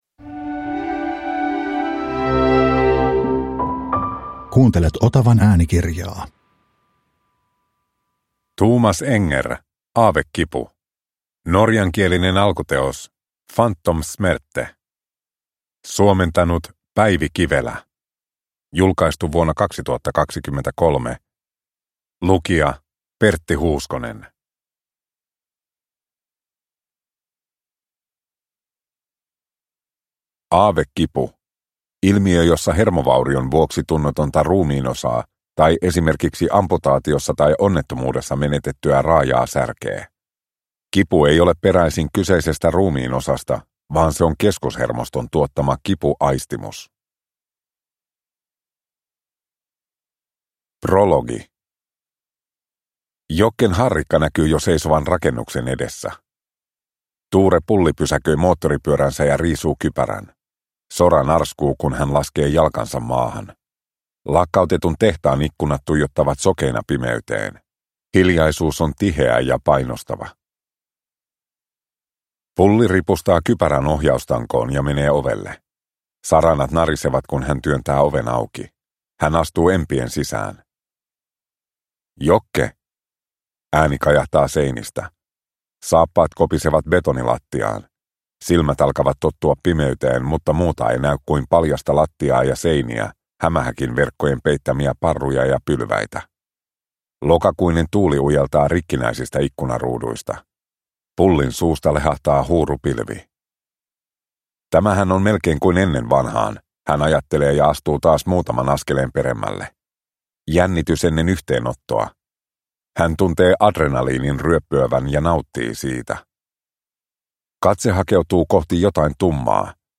Aavekipu – Ljudbok